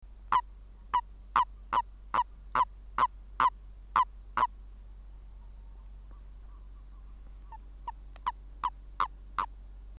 Audiodateien, nicht aus dem Schutzgebiet
Erdkröte UB
bufo bufo erdkröte.mp3